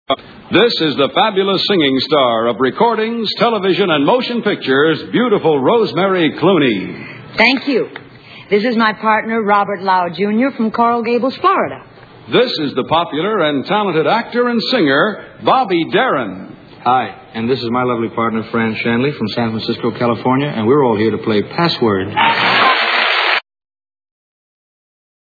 On January 6, 1963 Bobby Darin was a guest contestant on the night-time version of the popular CBS game show Password.
Bobby's introduction.